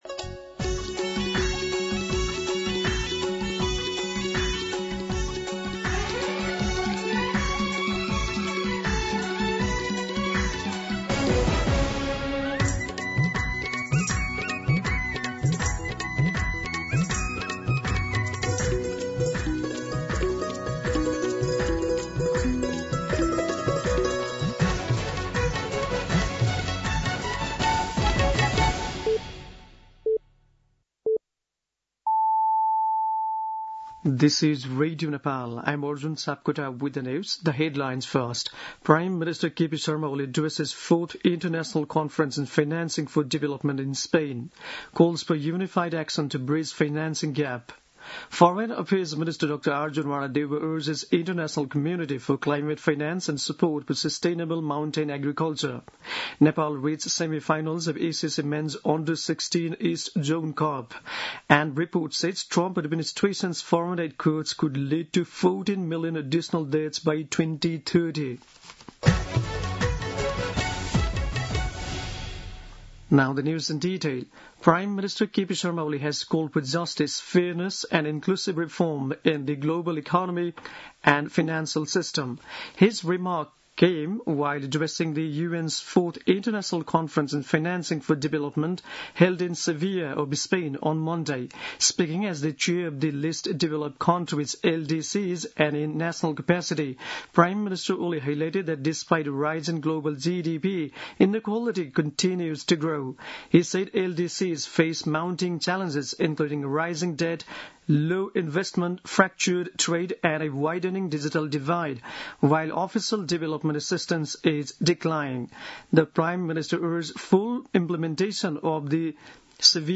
दिउँसो २ बजेको अङ्ग्रेजी समाचार : १७ असार , २०८२